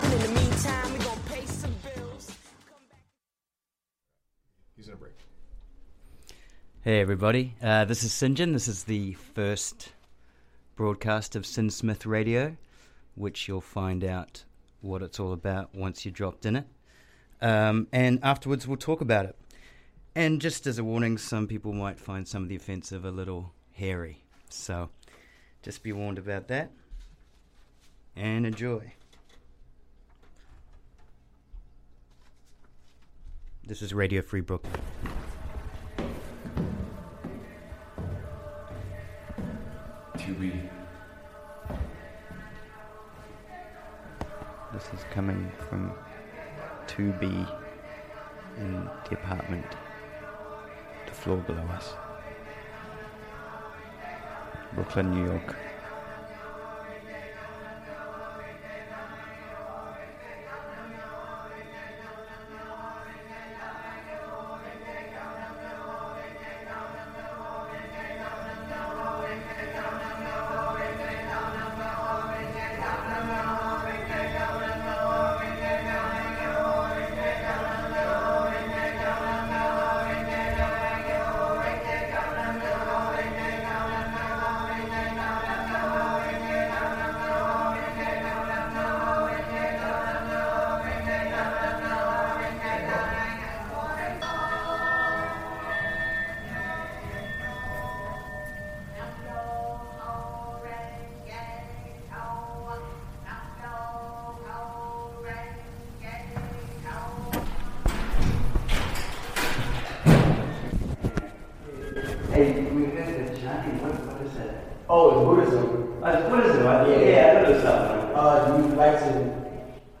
ALSO: DUE TO NATURE OF SHOW ACCEPT/EXPECT LESS THAN BROADCAST SOUND QUALITY AND LESS THAN FAMILY FRIENDLY CONTENT.